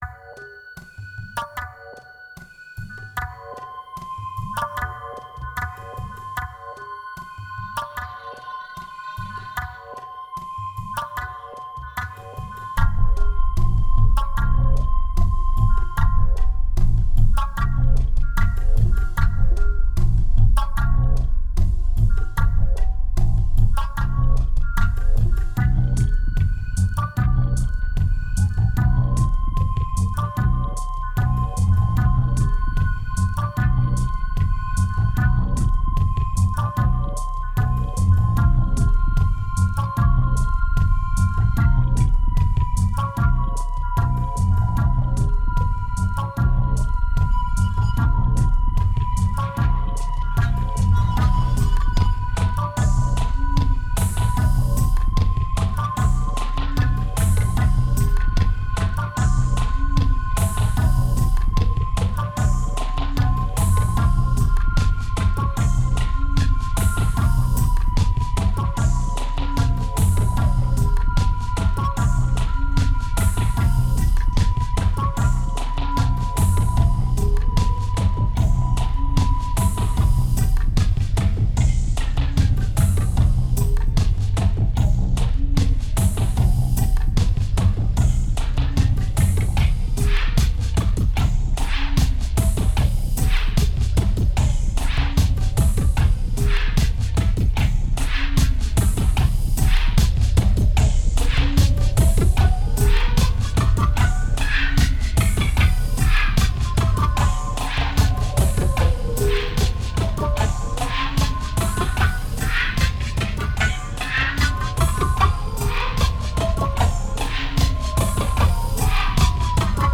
Tense and diverse